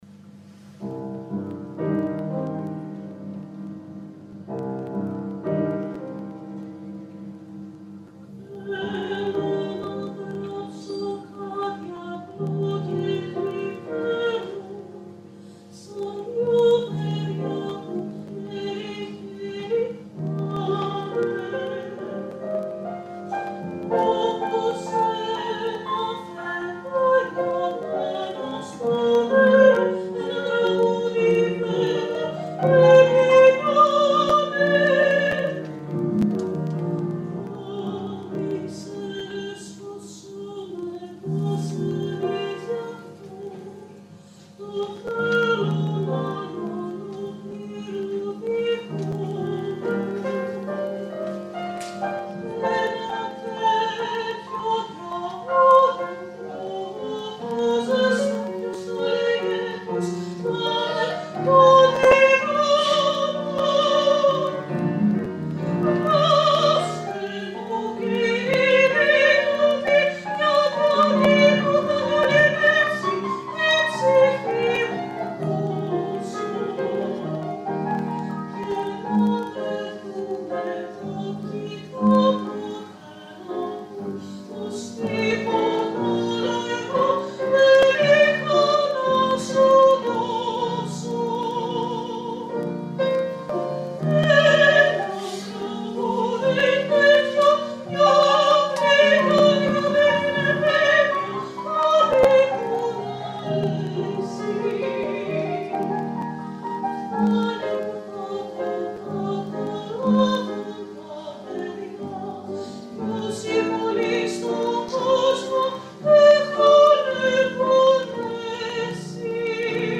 πιάνο Συναυλία στο Δημ Ωδείο Δράμας